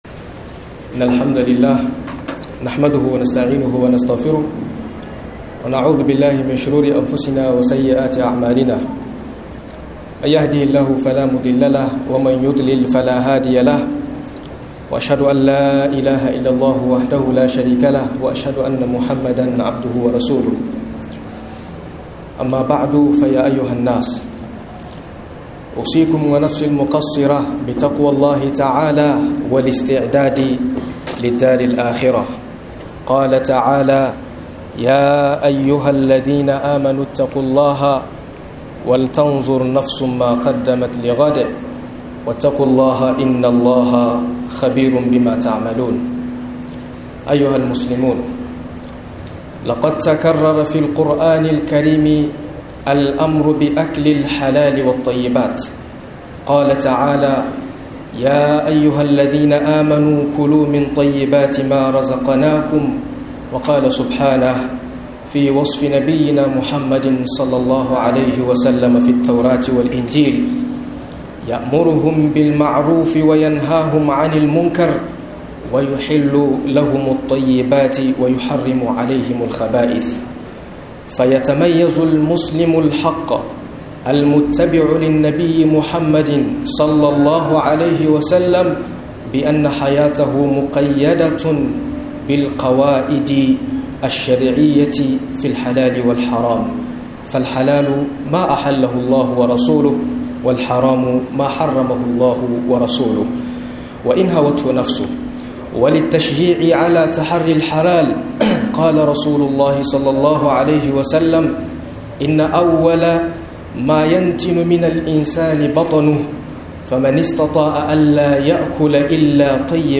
Jan Kunnay Game Da Cin Haram - MUHADARA